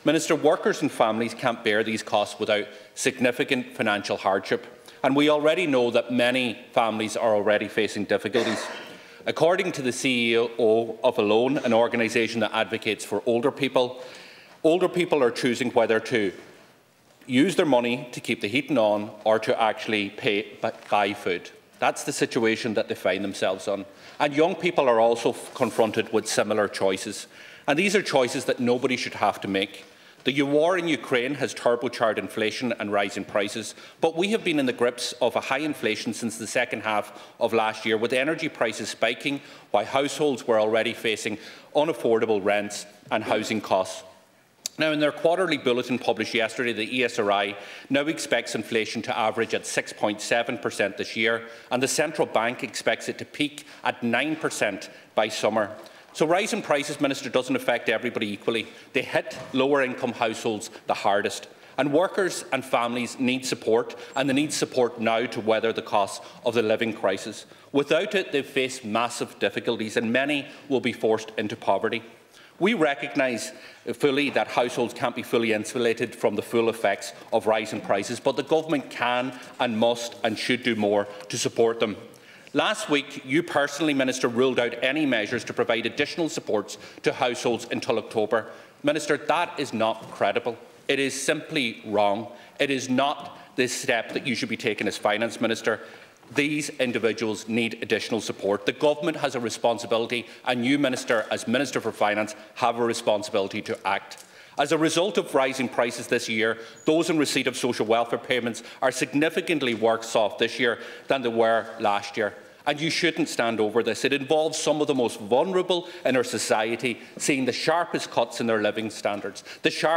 The Sinn Fein Finance Spokesperson has clashed in the Dail with the Finance Minister yet again over the rising cost of living.